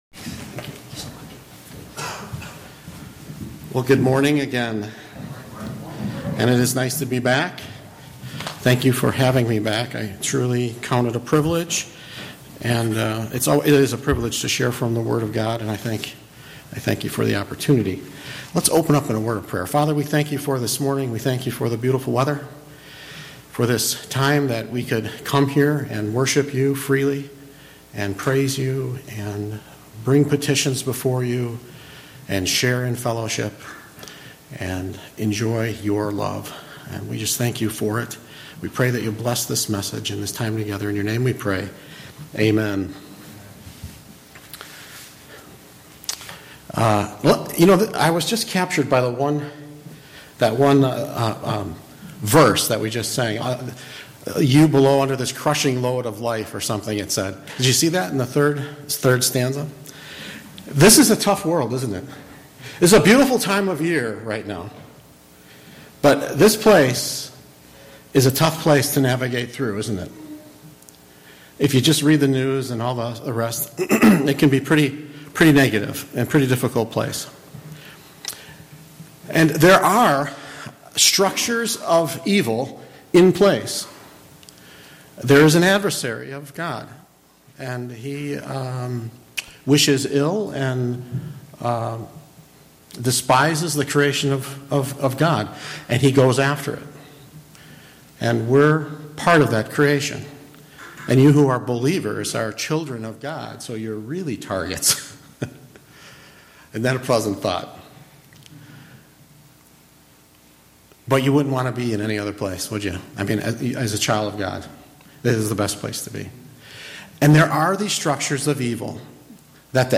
7-11 Service Type: Sunday Morning Worship Bible Text